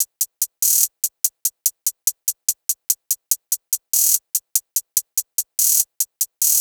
Closed Hats
HiHat (41).wav